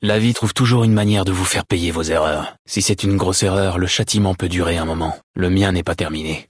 Catégorie:Dialogue audio de Fallout: New Vegas